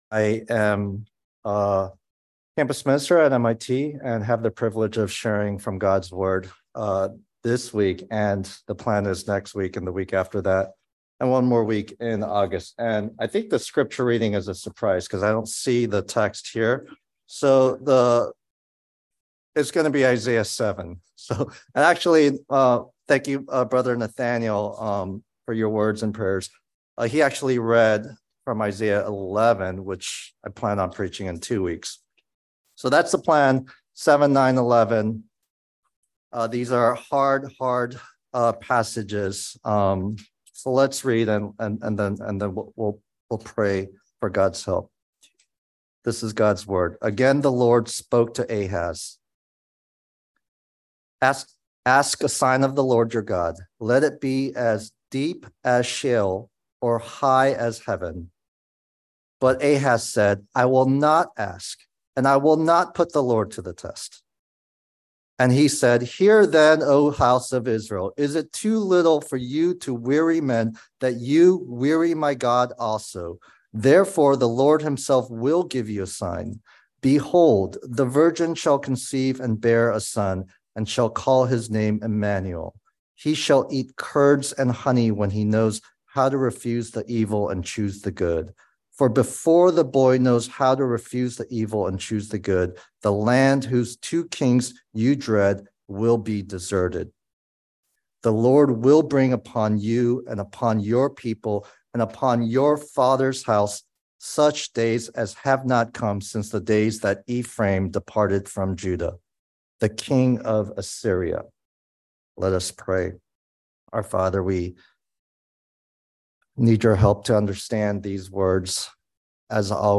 by Trinity Presbyterian Church | Jul 17, 2023 | Sermon